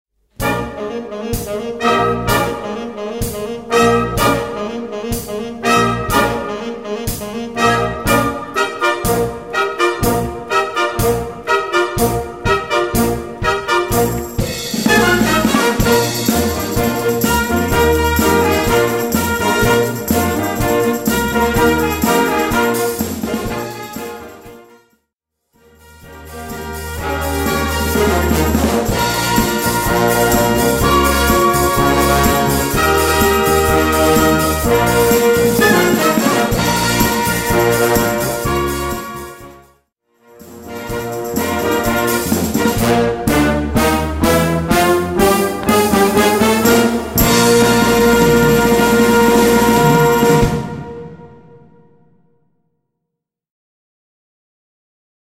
Gattung: 5-Part Flexible + Percussion
Besetzung: Blasorchester